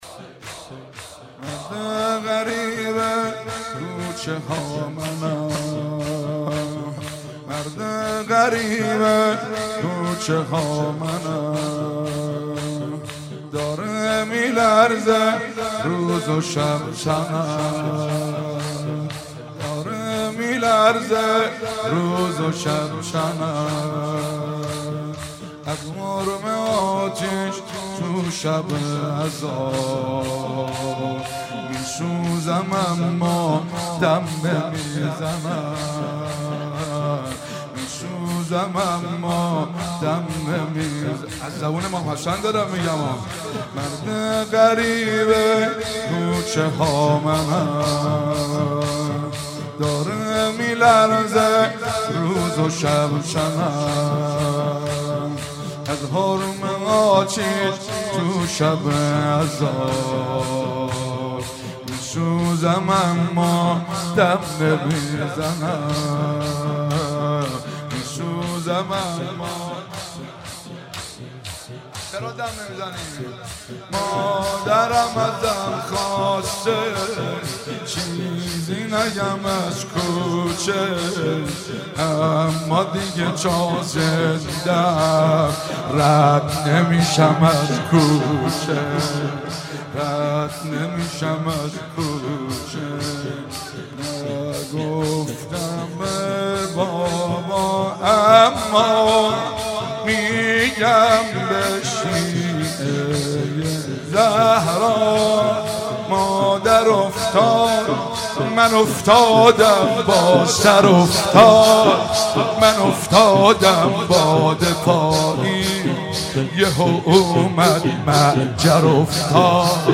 شب سوم فاطمیه 95 -زمینه - مرد غریبه کوچه ها منم
مداحی